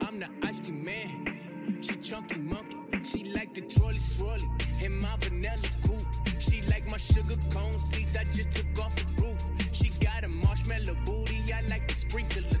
Sound Buttons: Sound Buttons View : Ice Cream Man Alert
ice-cream-man-alert-mp3.mp3